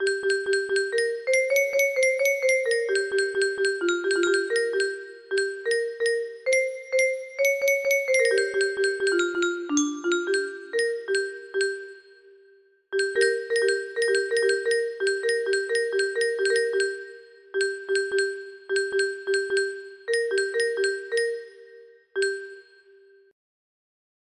Notes on Bd blues scale music box melody